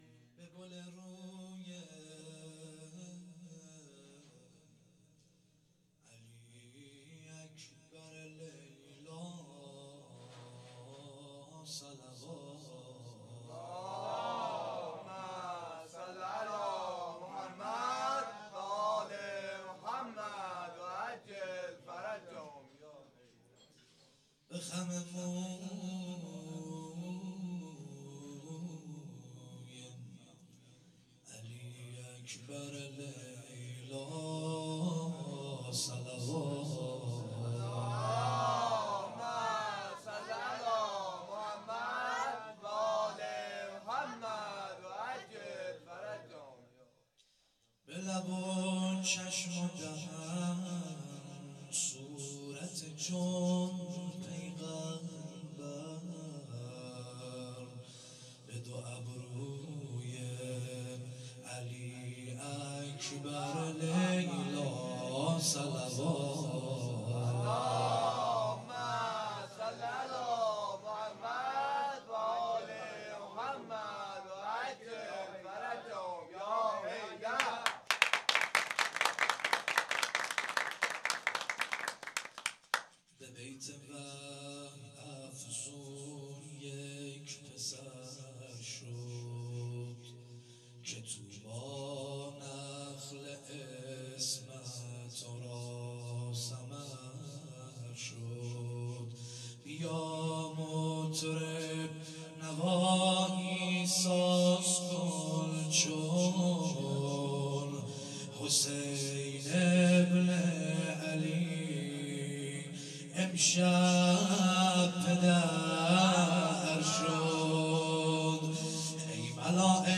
هیئت حضرت ابوالفضل(ع)ورامین - جشن ولادت حضرت علی اکبر(ع) ۹۸ شعر خوانی